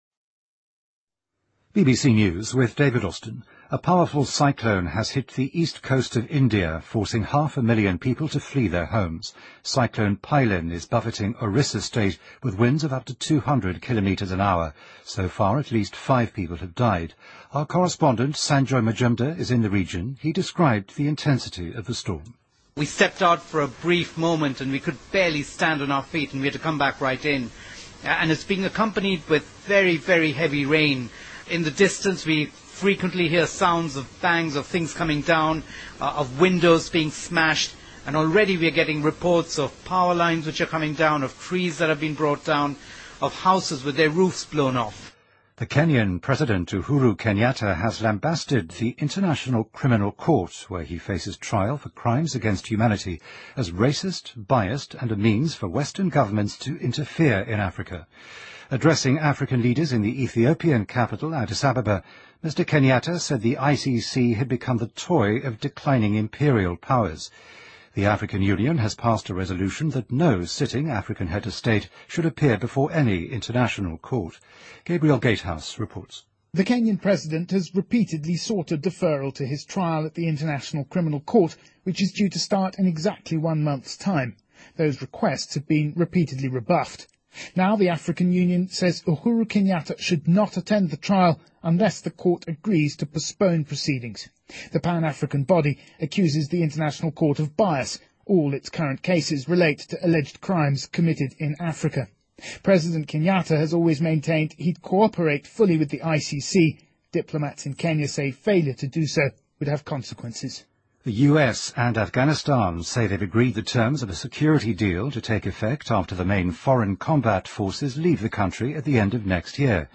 BBC news,印度东南海岸发生强飓风